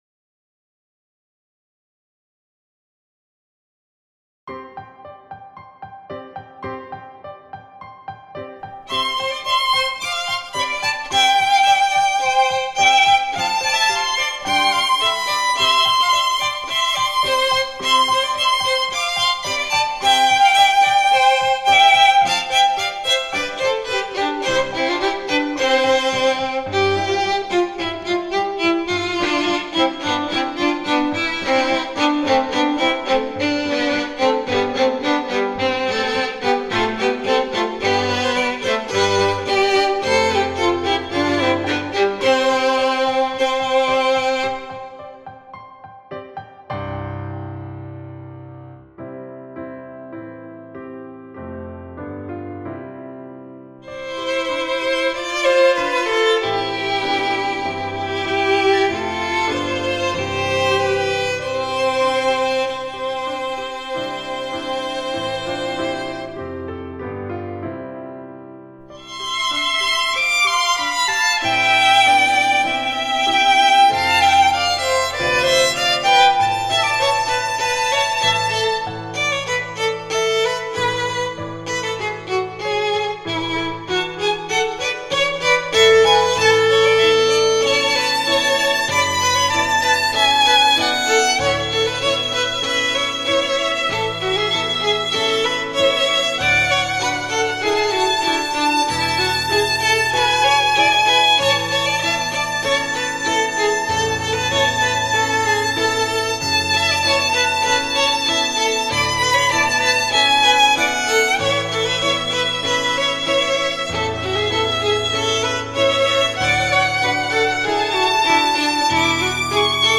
Instrumentation: 2 Violins with Piano accompaniment